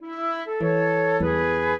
flute-harp
minuet6-9.wav